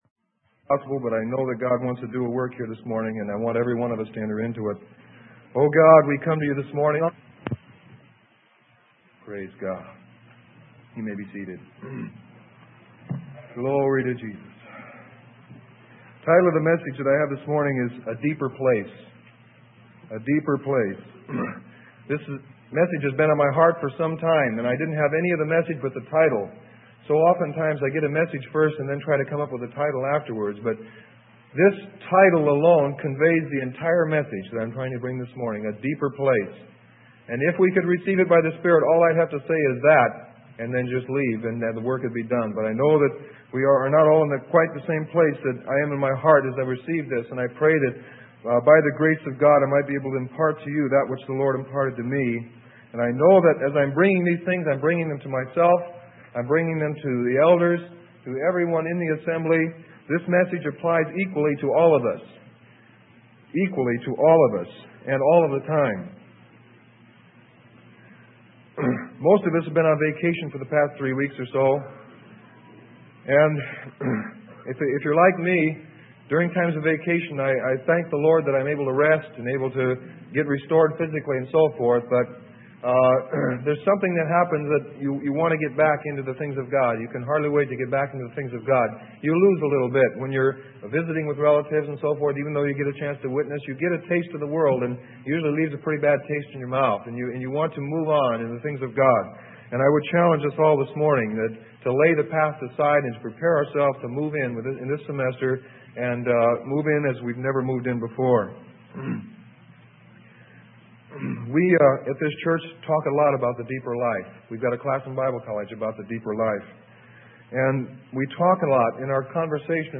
Sermon: A Deeper Place - Freely Given Online Library